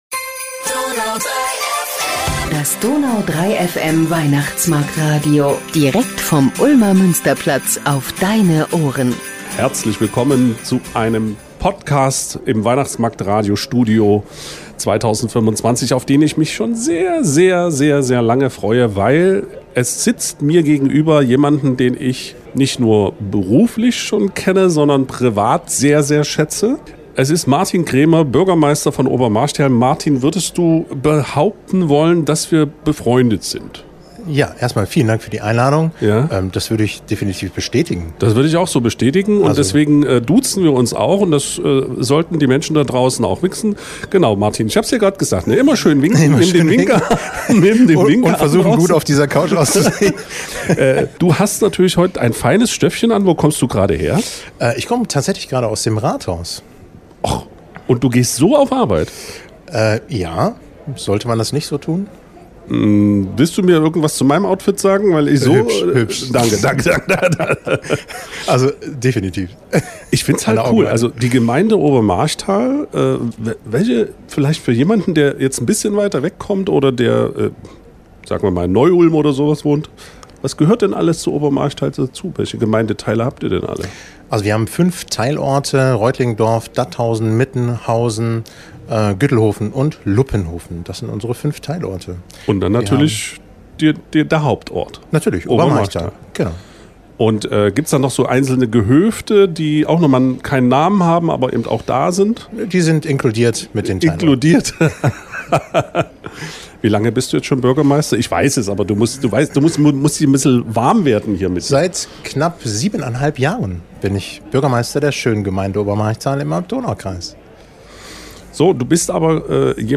Das DONAU 3 FM Weihnachtsmarktradio mit Martin Krämer, Bürgermeister von Obermarchtal ~ Ulmer Weihnachtsmarkt-Podcast Podcast